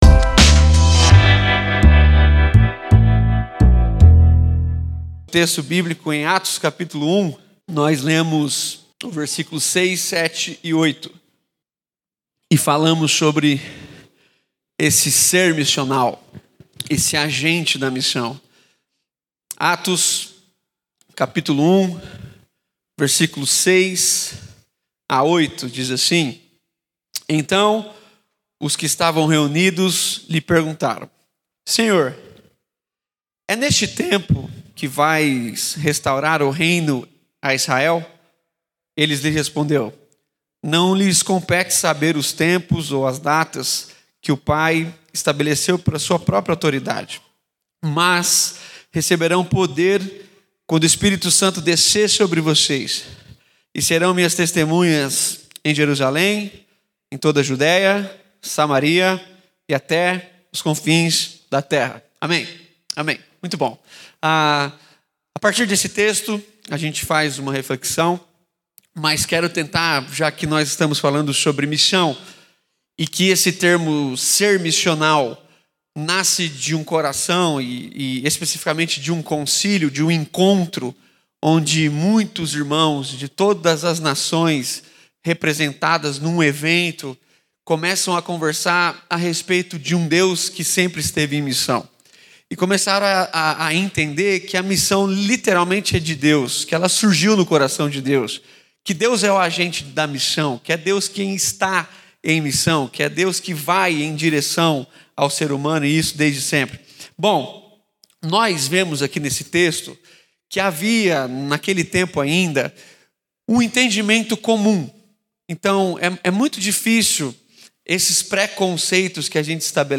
Conferência Missionária de 2022.